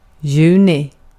Ääntäminen
IPA: /ˈke.sæˌkuː/